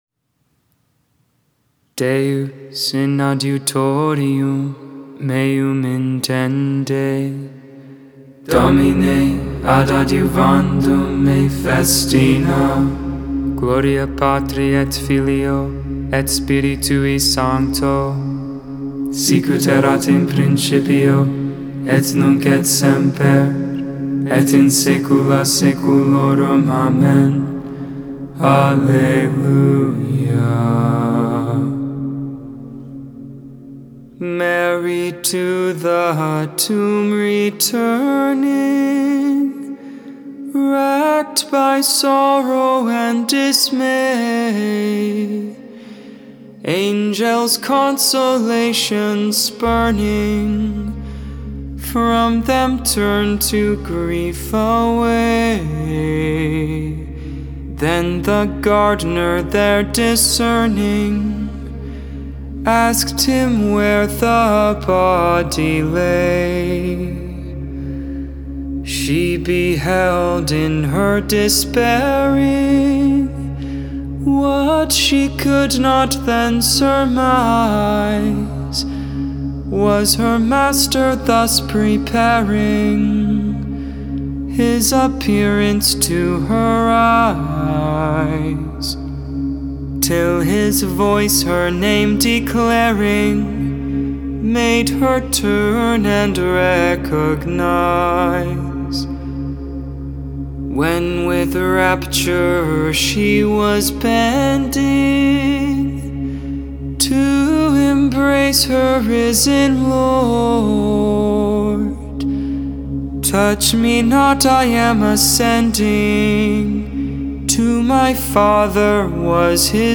7.22.22 Vespers, Friday Evening Prayer